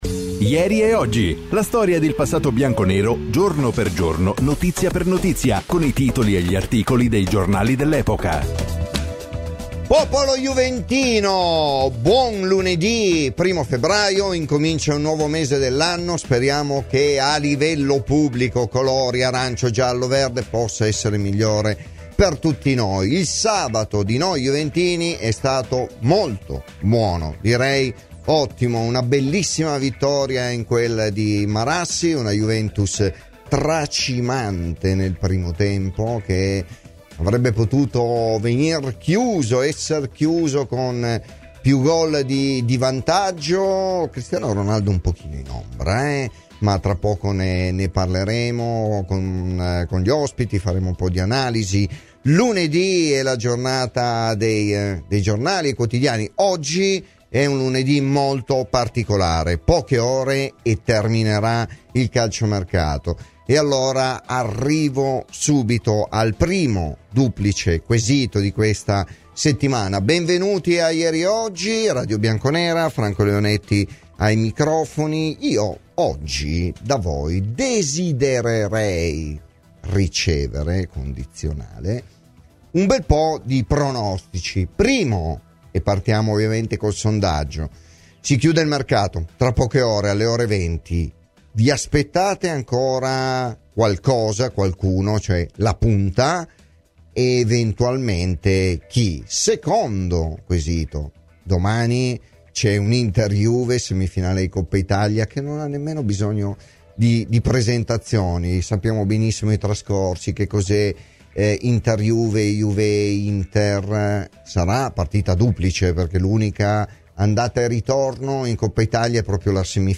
Clicca sul podcast per l’intervista integrale.